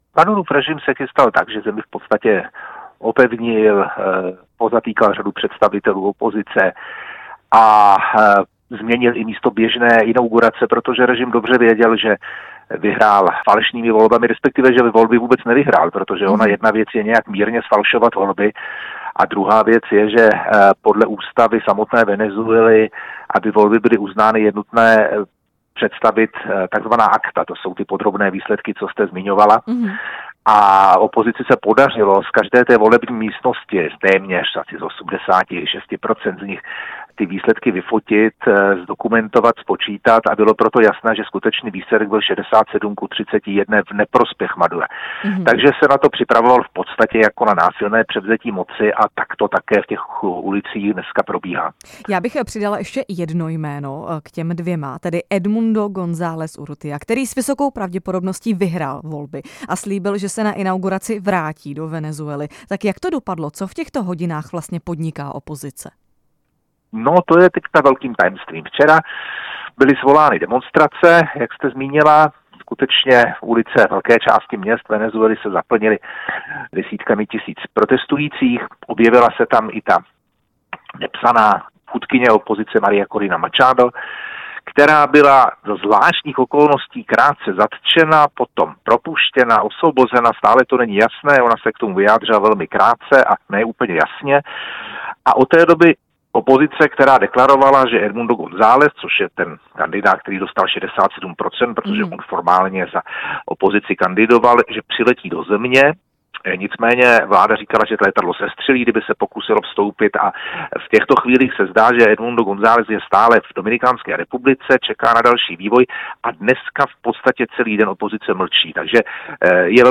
Ivan Pilip host vysílání Radia Prostor